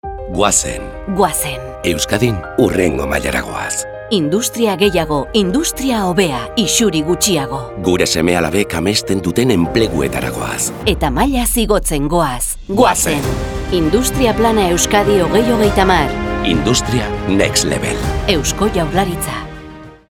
Irrati-iragarkiak